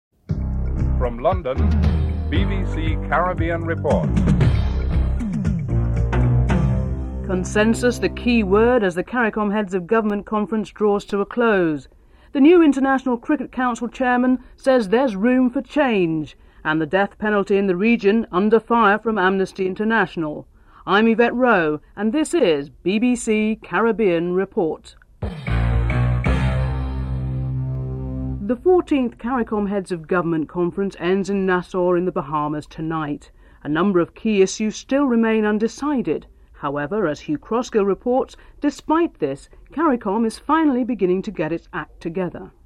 1. Headlines (00:00-00:33)
Interview with P.J. Patterson, Prime Minister of Jamaica.